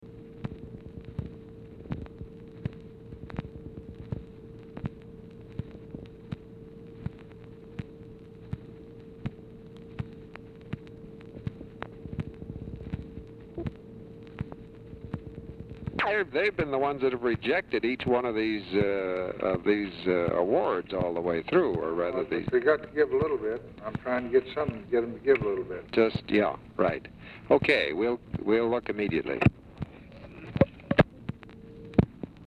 Telephone conversation # 2991, sound recording, LBJ and WALTER HELLER, 4/10/1964, 3:40PM | Discover LBJ
Format Dictation belt